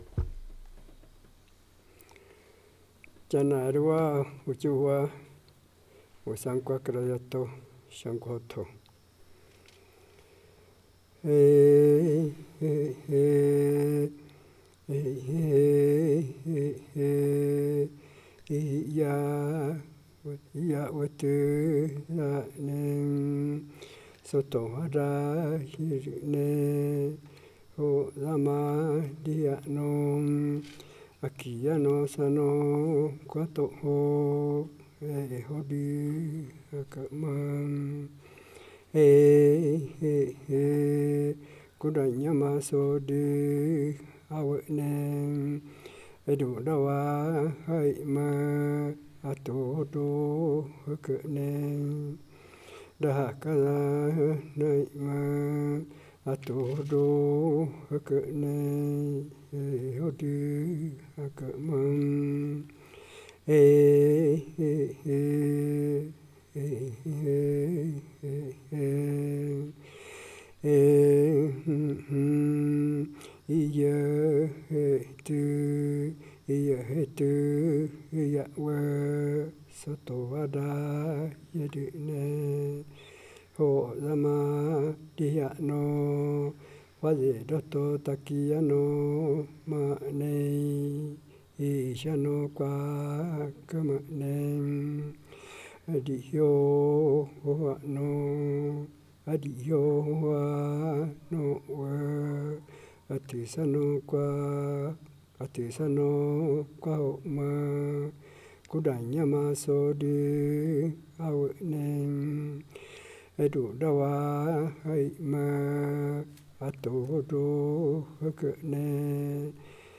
Execução do motivo melódico do canto Wesankwa (kädäijhato shankwajootojo). acchudi ou yaichuumadö. Cantador